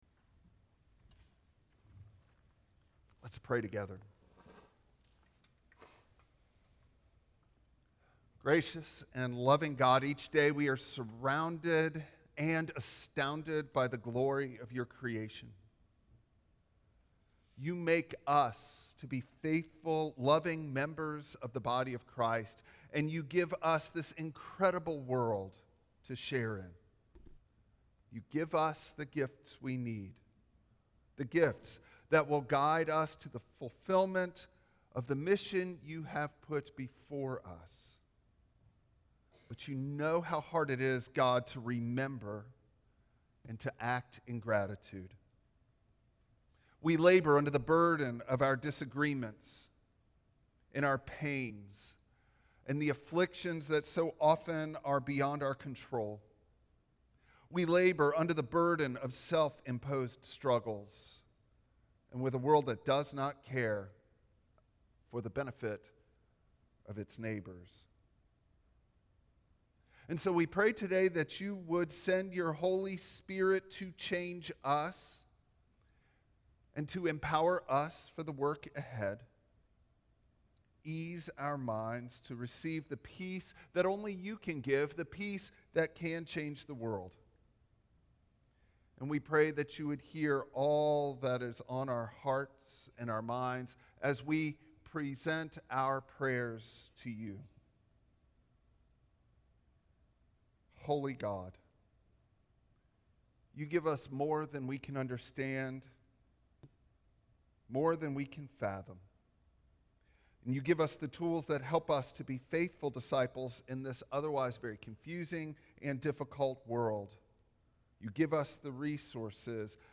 Passage: Acts 9:1-6 Service Type: Traditional Service Bible Text